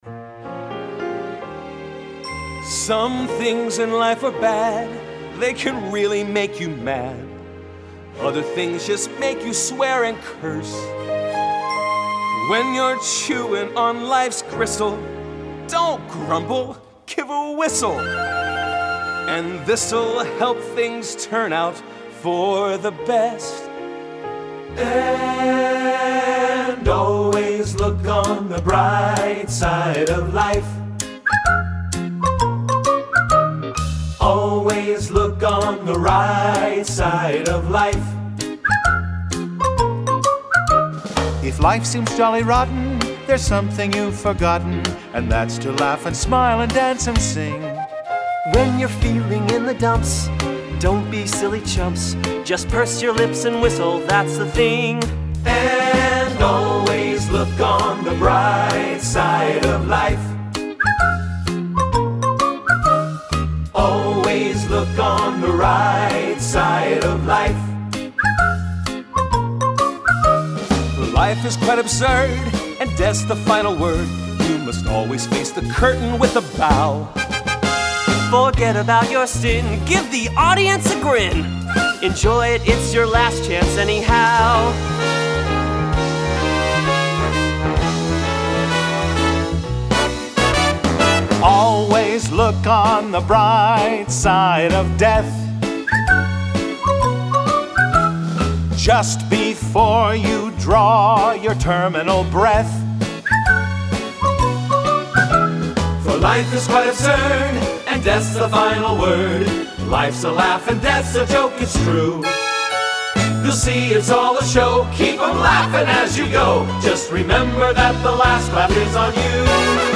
Voicing: TTB